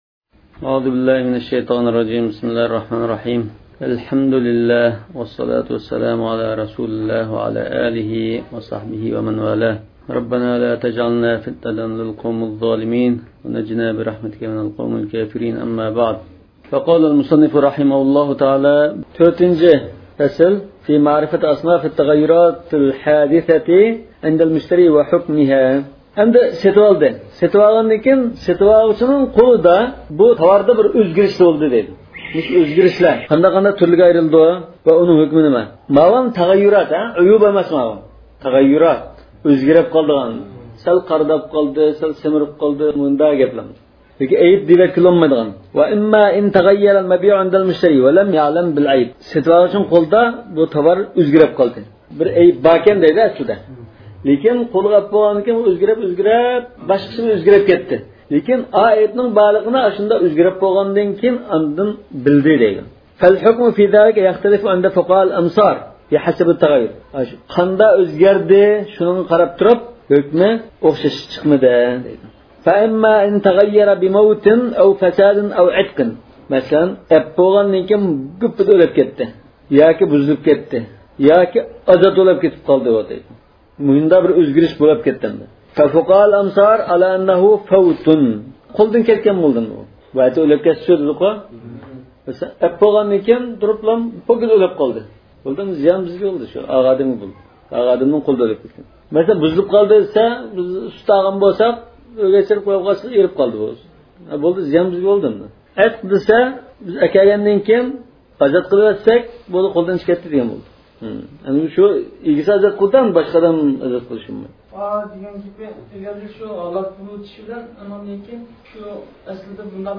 ئاۋازلىق دەرسلەر